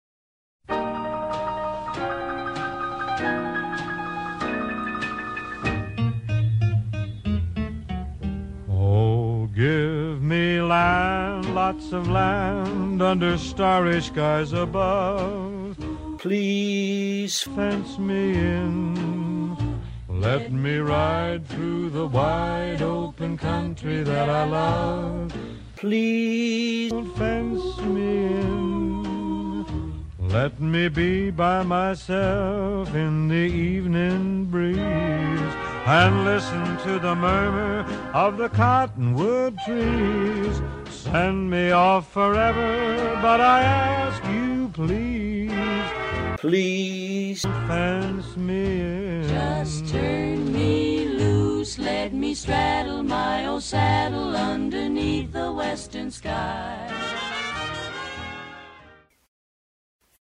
Music clip
slightly altered